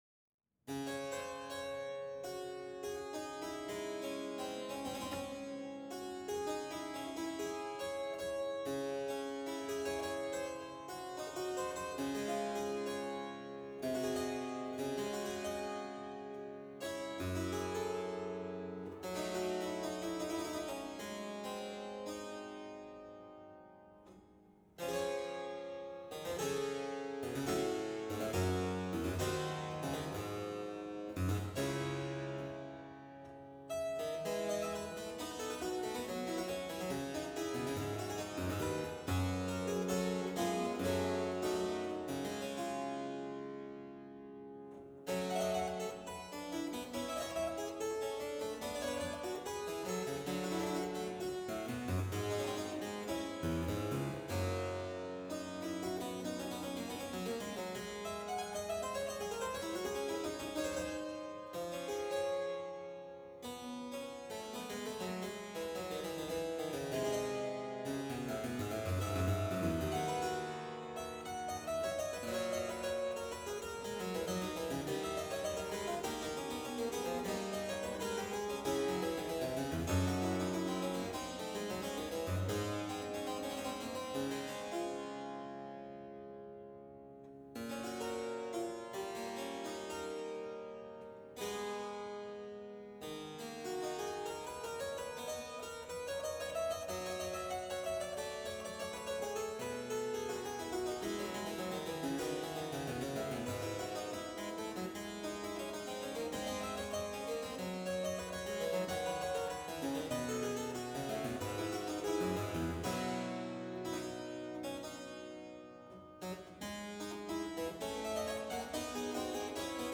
Harpsichord
These pieces were recorded on my copy of a Flemish double harpsichord at different events in the Chapel of the Holy Spirit, Newman College, the University of Melbourne.